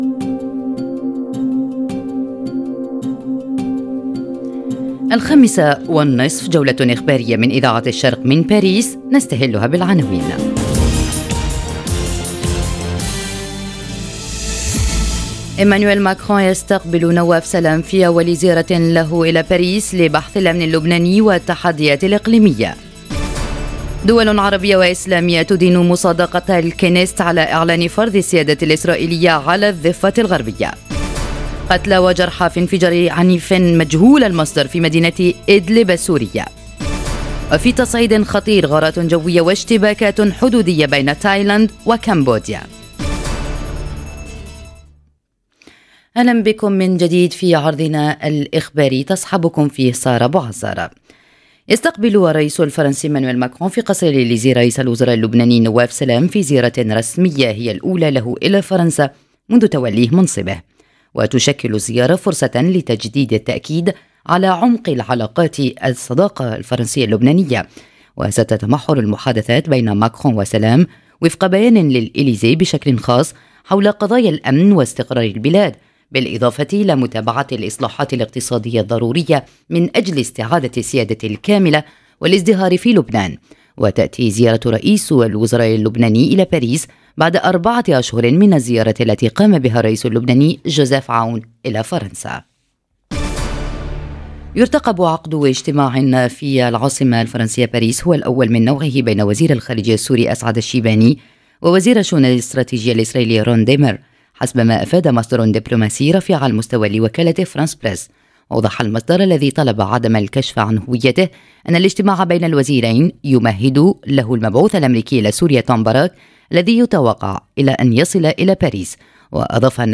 نشرة أخبار المساء: نواف سلام في باريس، ودول عربية واسلامية تدين مصادقة الكنيست على فرض السيادة الإسرائيلية على الضفة - Radio ORIENT، إذاعة الشرق من باريس